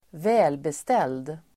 Ladda ner uttalet
Uttal: [²v'ä:lbestel:d]